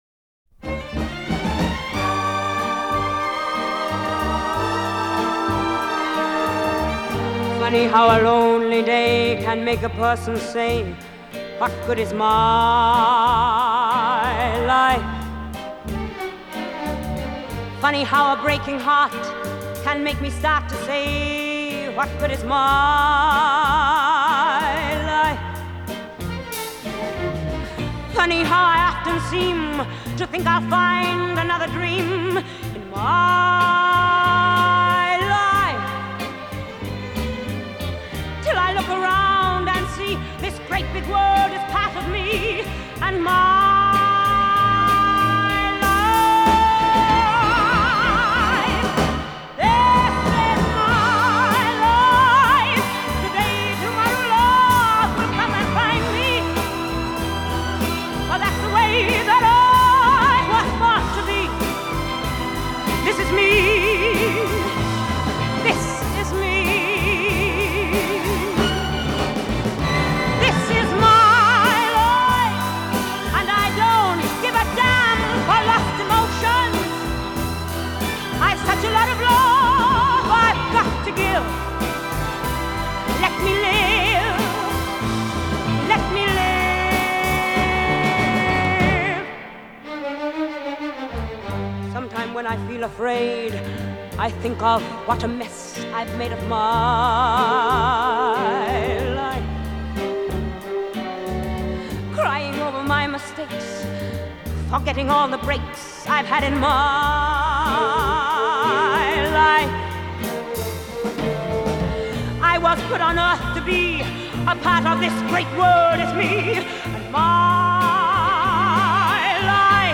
lung-busting anthem
really let’s it rip